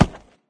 woodgrass.ogg